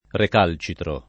vai all'elenco alfabetico delle voci ingrandisci il carattere 100% rimpicciolisci il carattere stampa invia tramite posta elettronica codividi su Facebook ricalcitrare v.; ricalcitro [ rik # l © itro ] — anche recalcitrare : recalcitro [ rek # l © itro ]